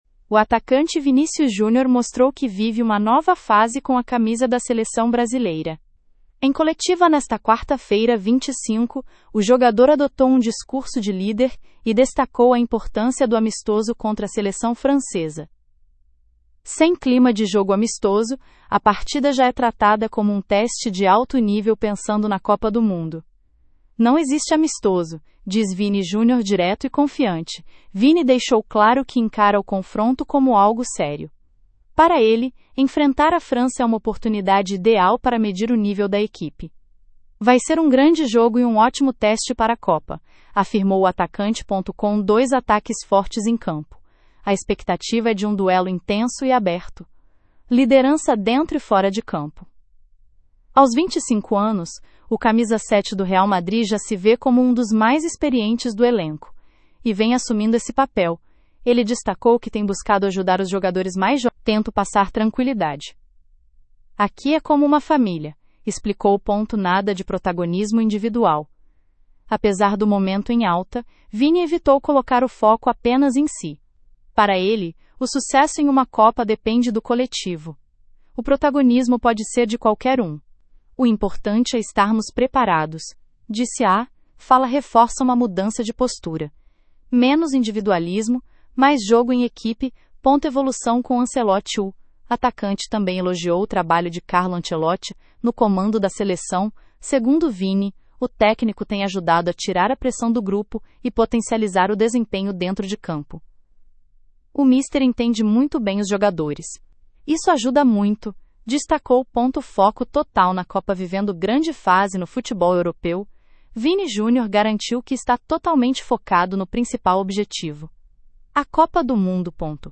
Em coletiva nesta quarta-feira (25), o jogador adotou um discurso de líder e destacou a importância do amistoso contra a Seleção Francesa.